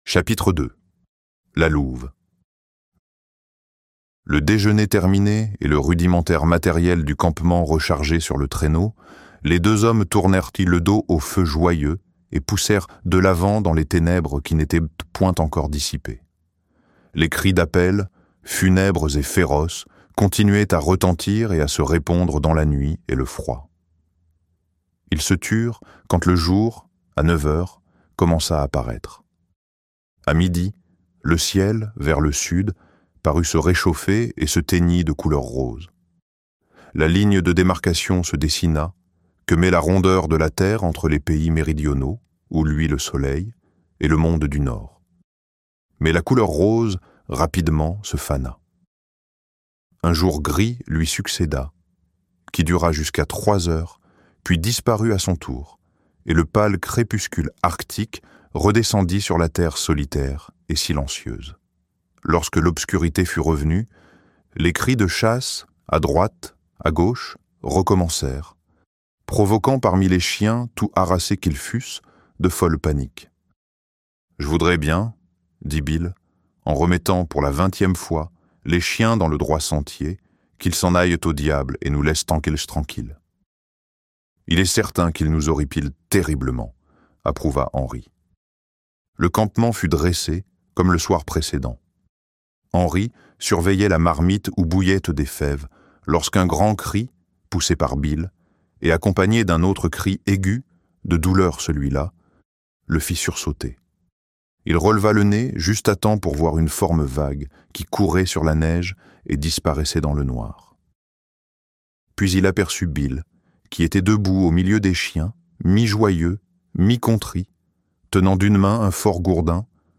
Croc-Blanc - Livre Audio